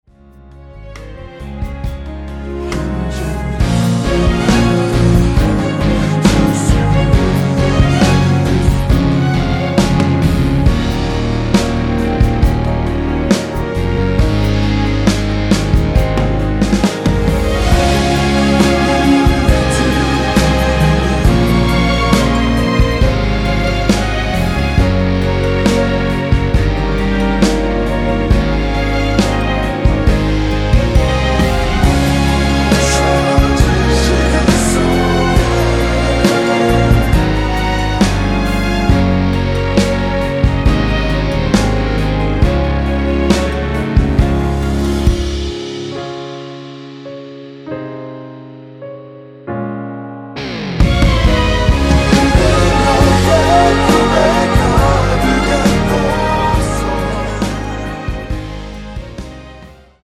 원키에서(-3)내린 코러스 포함된 MR입니다.
Db
앞부분30초, 뒷부분30초씩 편집해서 올려 드리고 있습니다.